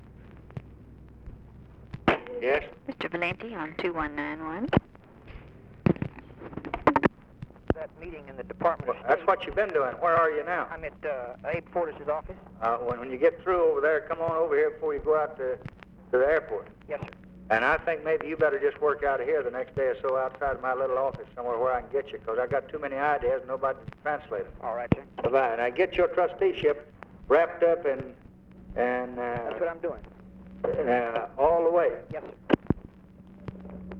Conversation with JACK VALENTI, December 4, 1963
Secret White House Tapes